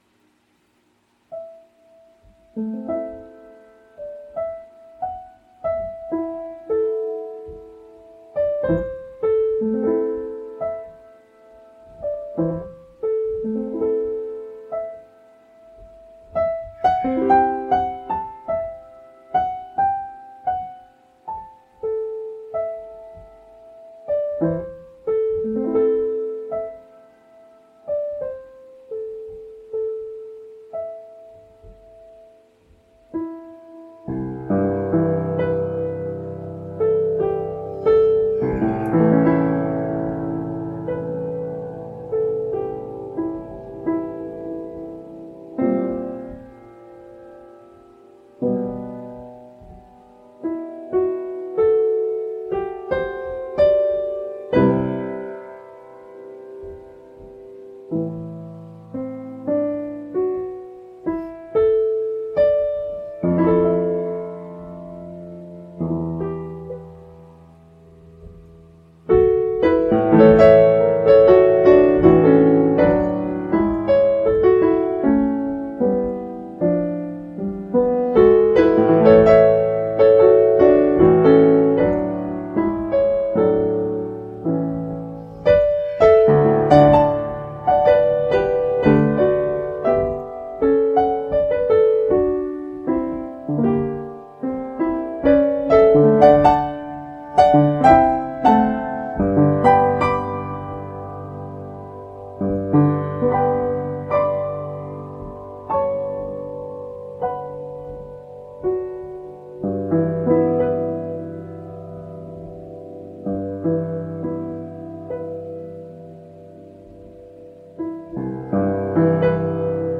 These musical poems for piano were compositions based on Walt Whitman’s “In Cabin’d Ships at Sea” from Leaves of Grass (1891-92):